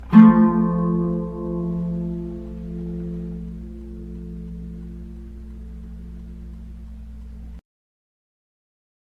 • The Bajo Sexto is a twelve-string guitar.
G chord